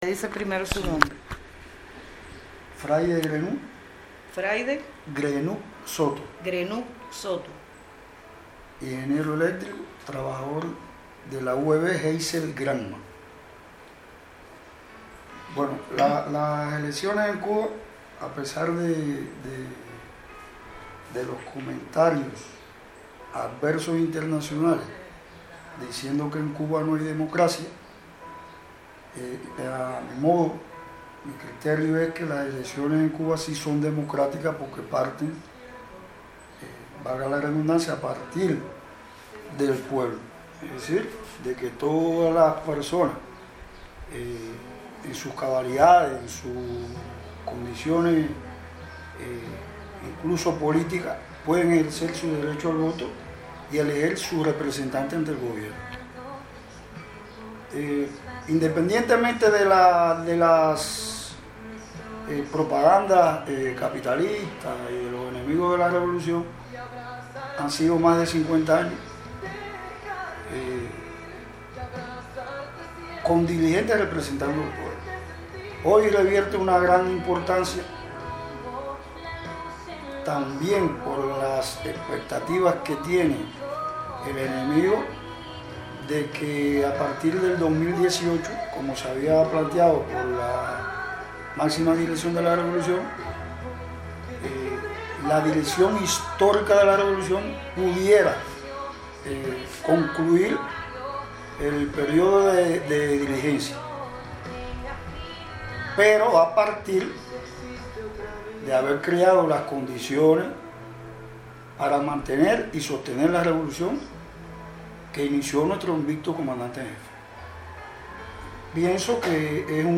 Palabras del ingeniero eléctrico